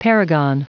Prononciation du mot paragon en anglais (fichier audio)
Prononciation du mot : paragon
paragon.wav